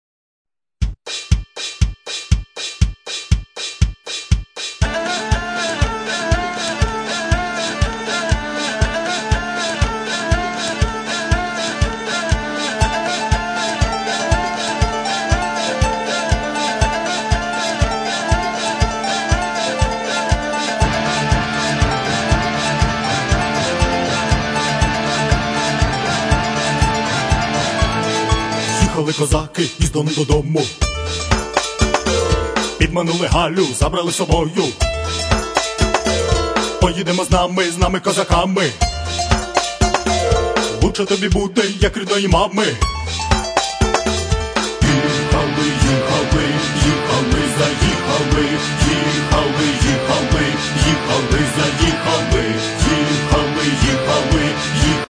Бандура і Кобза (22)